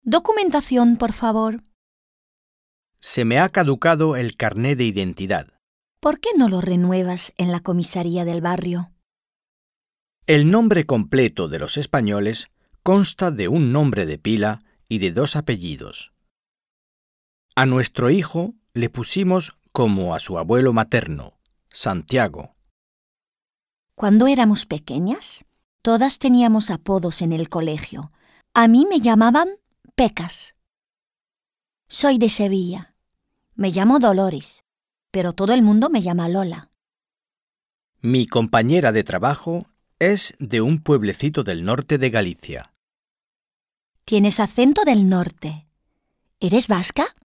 Un peu de conversation - L'état civil